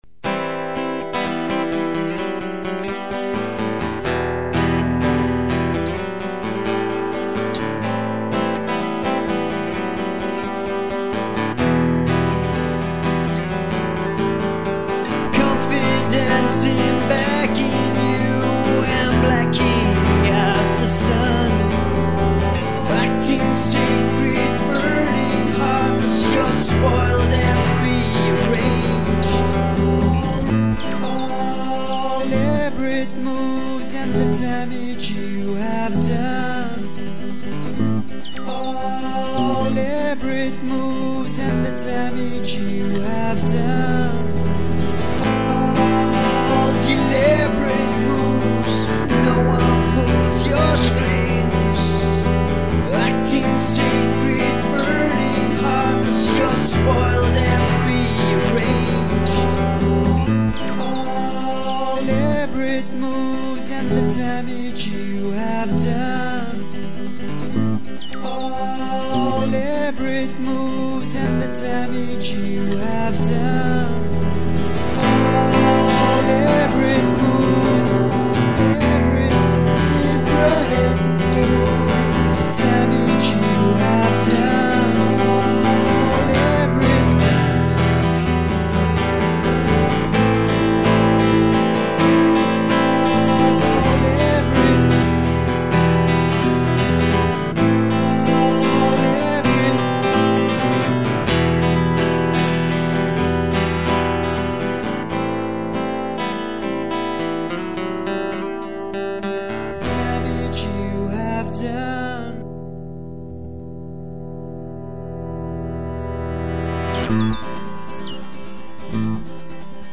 Acoustic demo
Raw-songs
Country
Folk